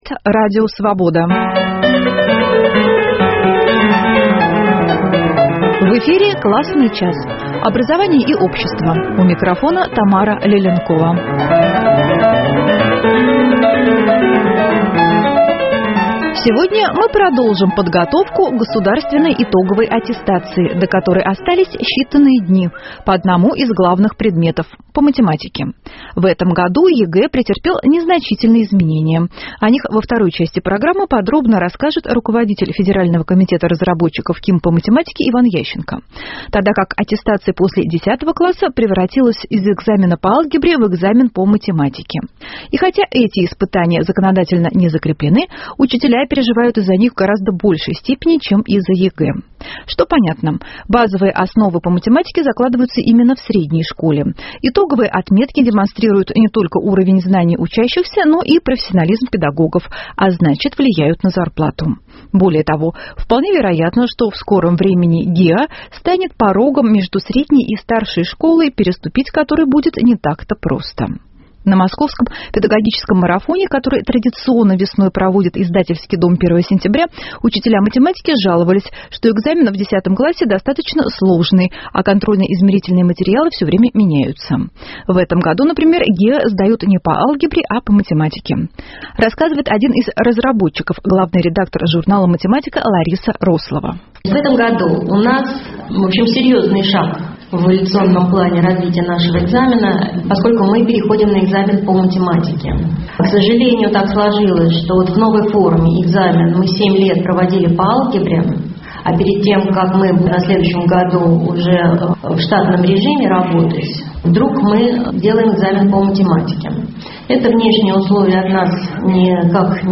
В этом выпуске программы принимают участие разработчики ЕГЭ, методисты и учителя, а также школьники, которые не только готовятся к экзамену, но и рассматривают варианты "подстраховки" на случай затруднений с ответами.